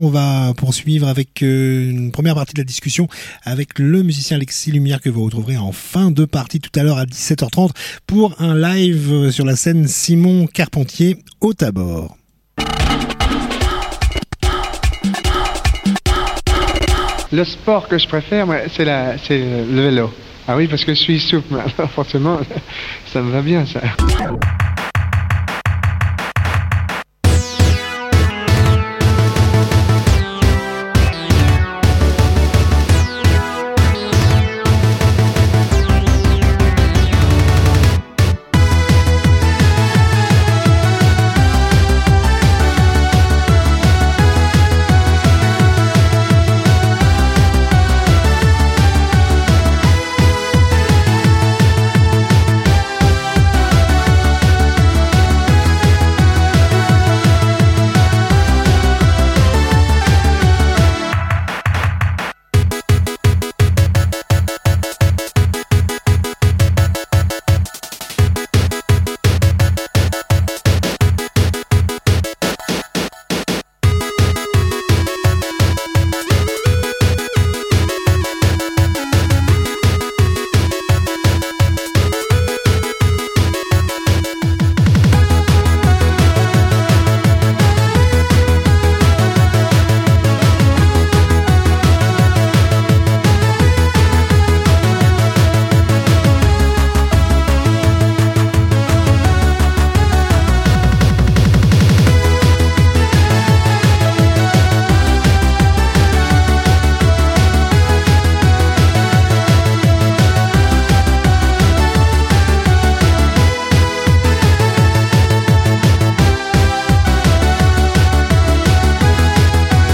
Emission spéciale